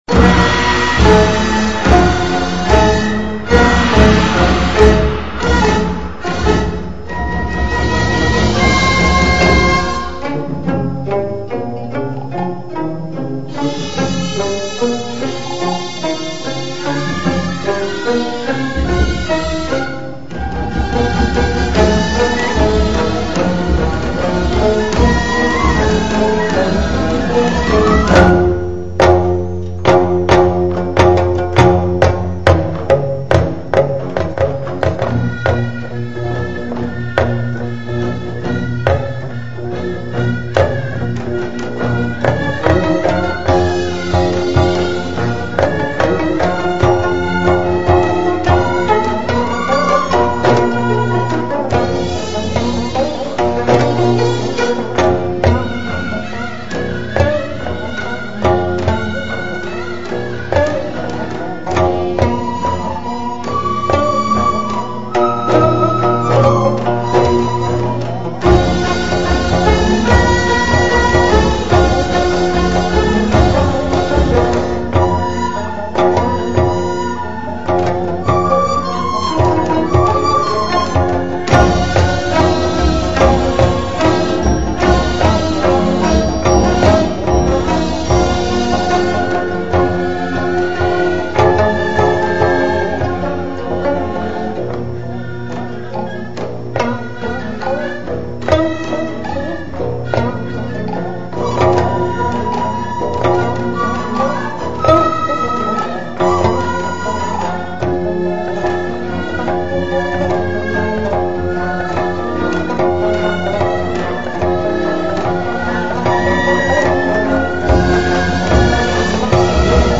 부산시립국악관현악단 연주
□ 봄을 주제로 한 거문고 협주곡 〔출강(出鋼)〕【부산초연】
※거문고
거기에 맞추어 음악을 너무 압축하면 음질이 너무 않 좋아서 부득이 두개로 나누었습니다.